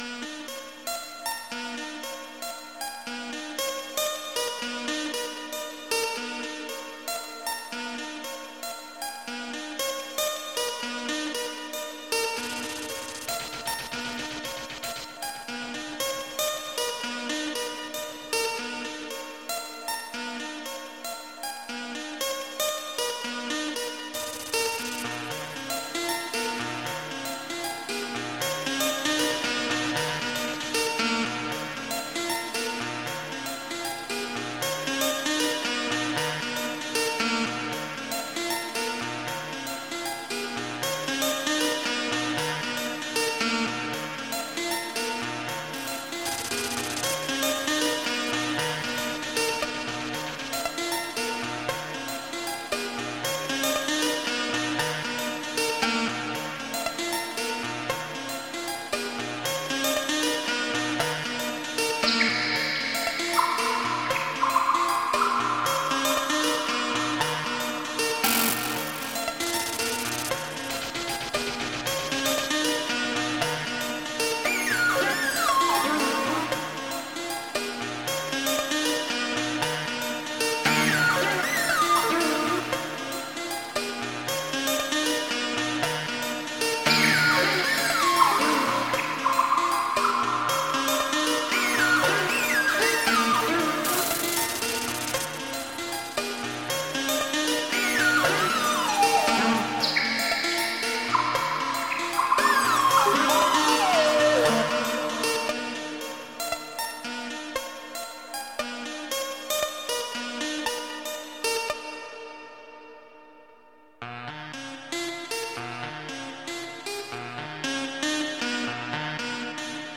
IDM
британский электронный дуэт